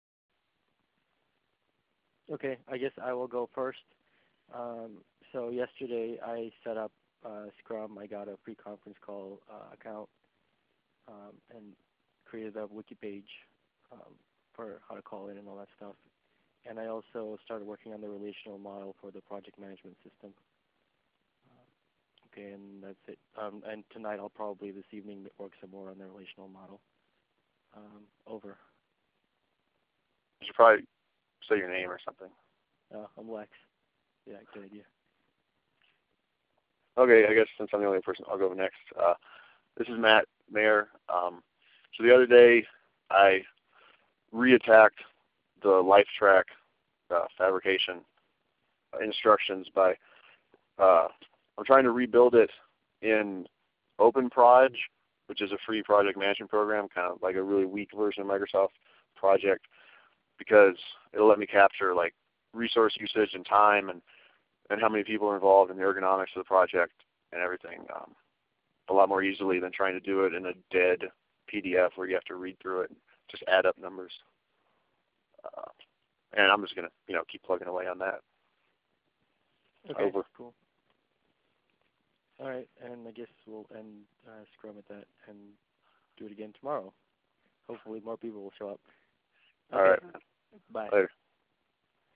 Meetings will be recorded and listed on this page.
Conference Call